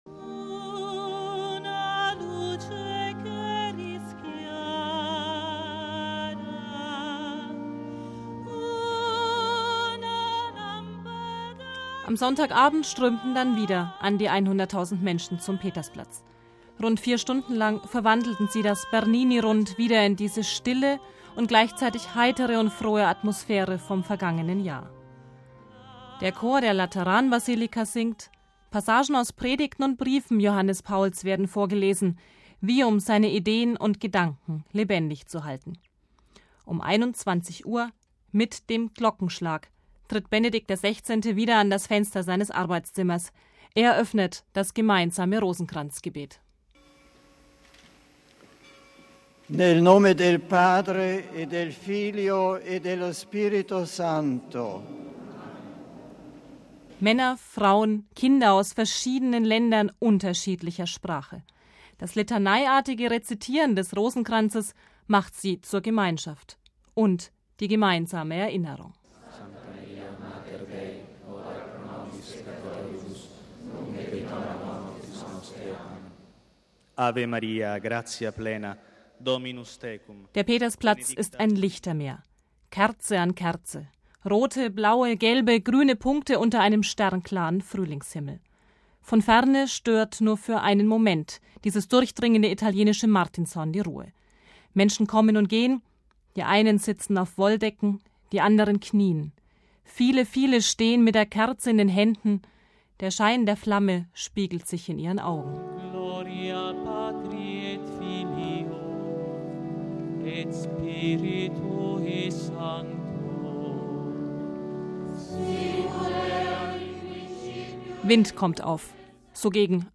MP3 … die Rufe nach der sofortigen Heiligsprechung – sie erklangen wieder, genau ein Jahr nach dem Tod Johannes Pauls II. bei der nächtlichen Gebetswache am Petersplatz.